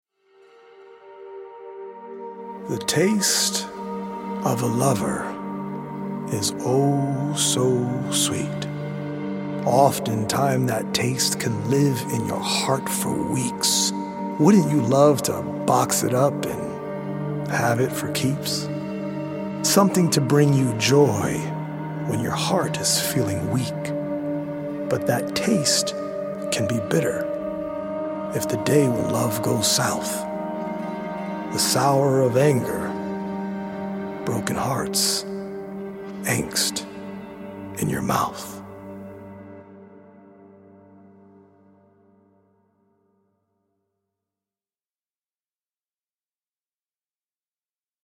healing Solfeggio frequency music